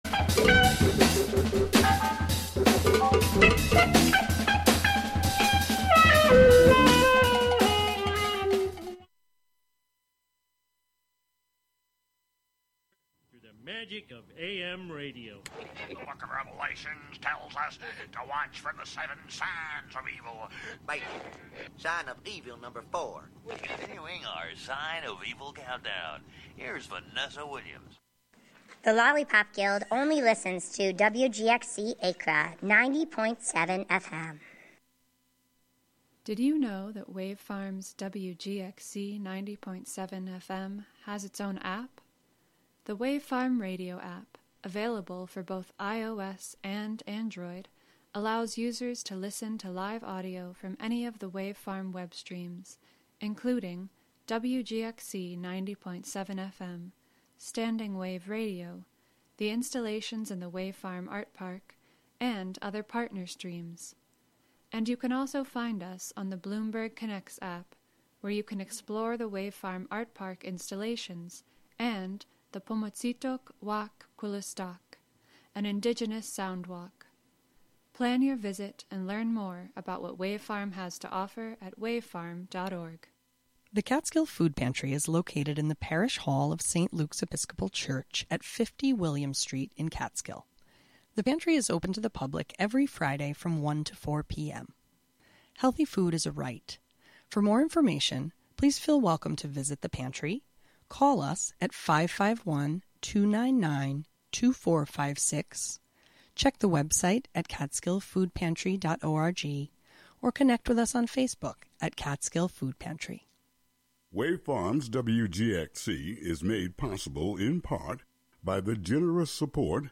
A SPLEN-DID hour of sound and music inspired by the recombinant qualities of food with occasional conversations about milk.